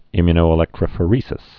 (ĭmyə-nō-ĭ-lĕktrə-fə-rēsĭs, ĭ-my-)